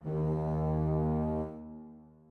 strings2_7.ogg